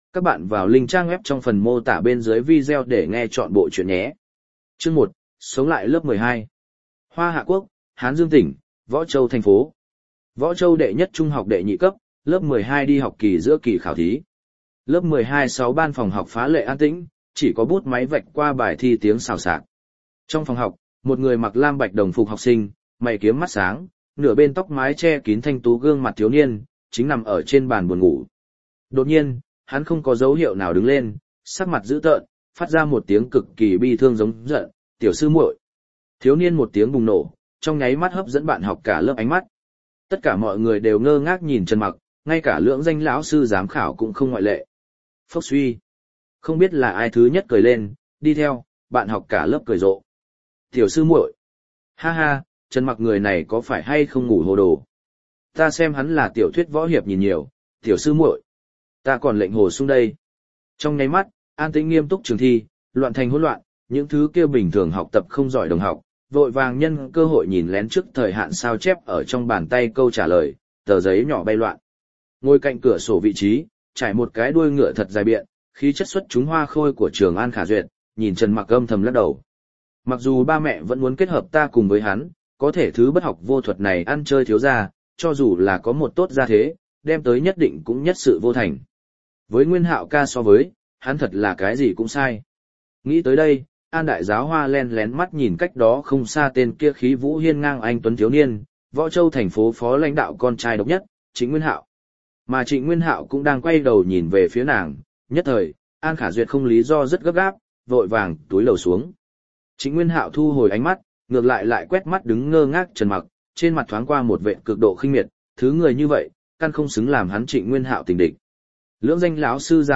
Đô Thị Chi Tối Cường Tiên Tôn Audio - Nghe đọc Truyện Audio Online Hay Trên TH AUDIO TRUYỆN FULL